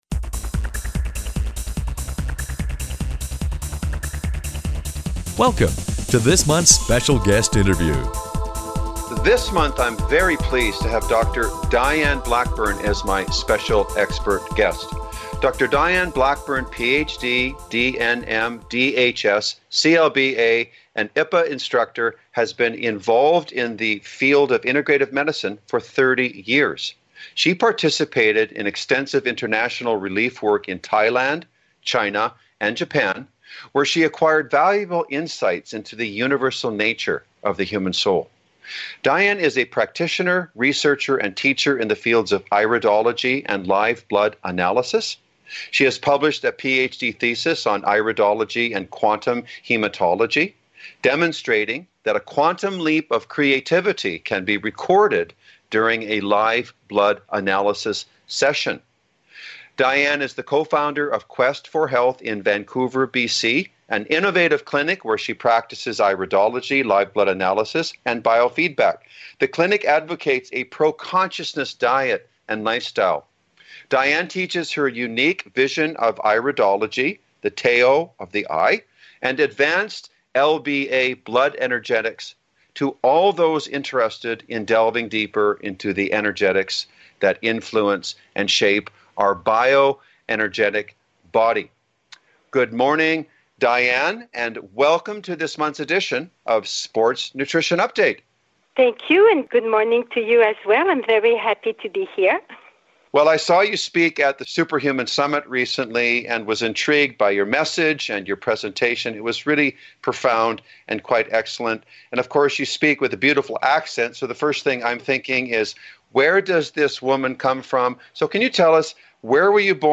Special Guest Interview Volume 15 Number 11 V15N11c